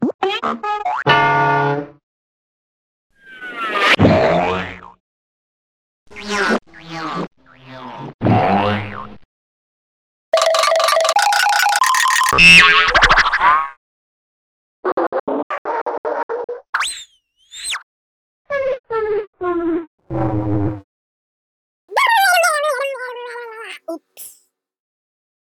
Collage of Cartoon Sounds
Category 🤣 Funny
animados boink cartoon collage comedy comic coo-koo crash sound effect free sound royalty free Funny